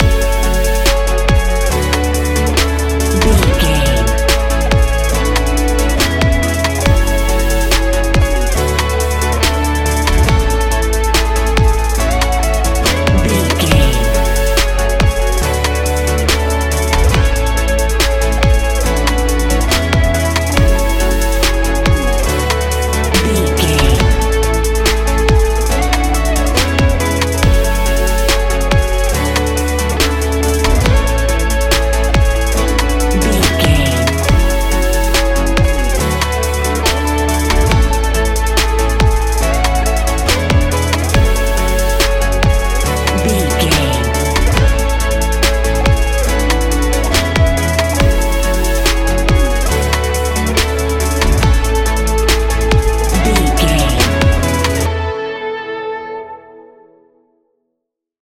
Ionian/Major
ambient
electronic
new age
downtempo
pads
drone